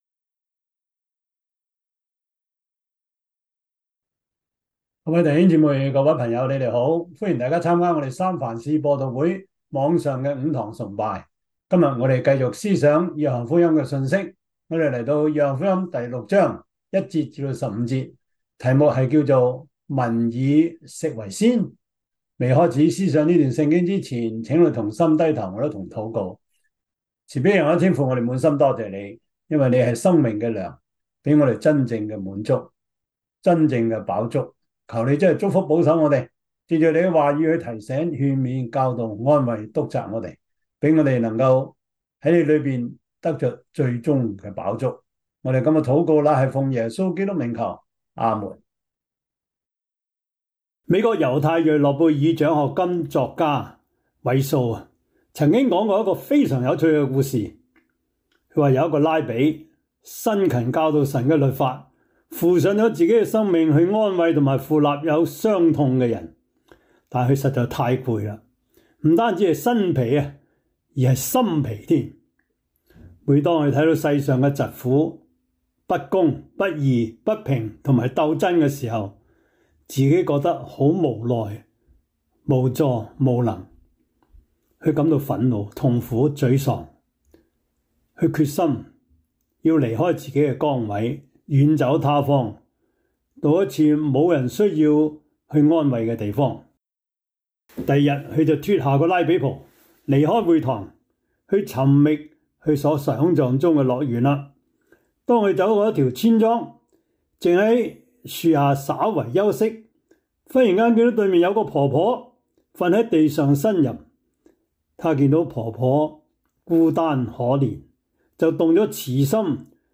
約翰福音 6:1-15 Service Type: 主日崇拜 約翰福音 6:1-15 Chinese Union Version